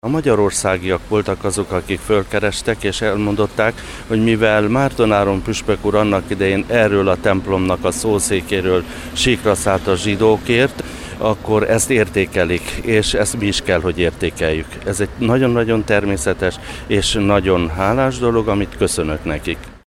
A Szent Mihály templom bejárata mellett álló Márton Áron szobornál rövid ünnepség keretében idézték meg szavait és szellemét.